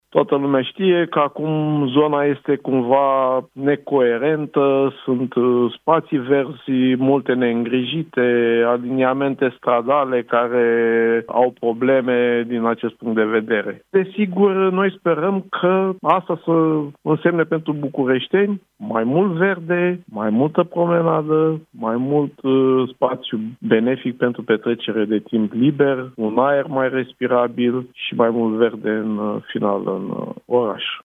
Unul dintre inițiatorii proiectului, consilierul USR Nicorel Nicorescu, pentru Europa FM: „Noi sperăm că asta va însemna pentru bucureșteni mai mult verde, mai multă promenadă”